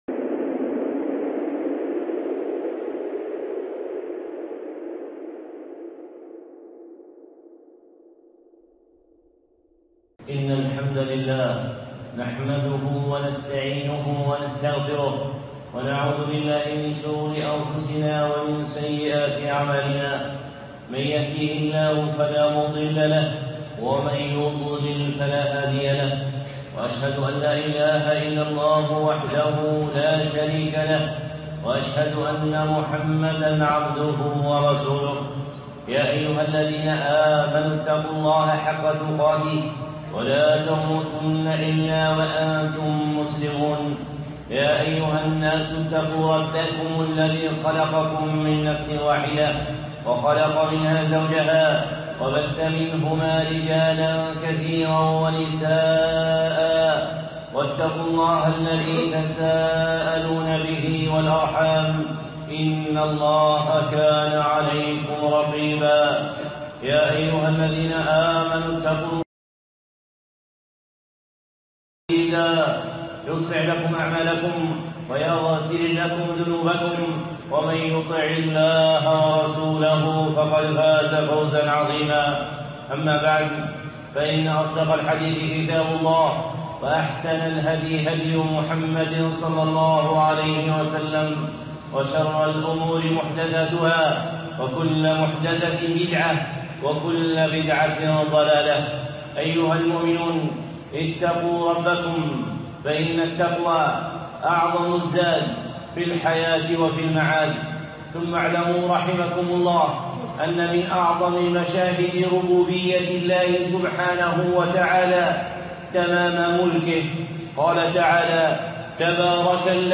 خطبة (لله الشفاعة جميعا